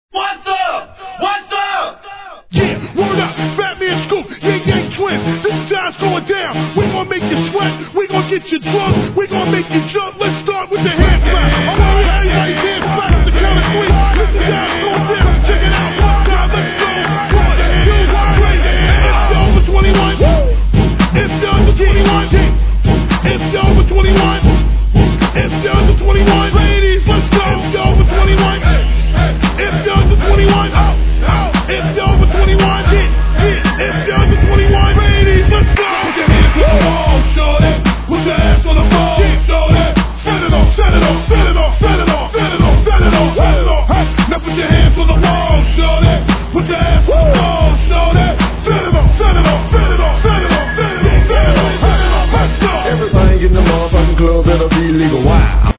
HIP HOP/R&B
アッパーなBOUNCE BEATで終始煽り煽り!!